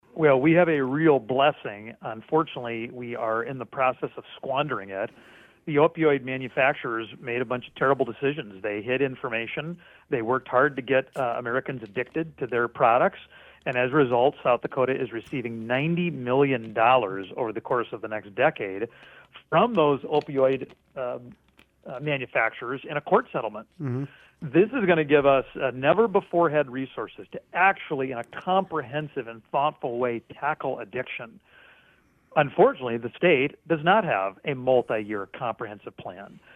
Johnson was asked about funding for these projects.  He discuss the opioid settlement reached with the state.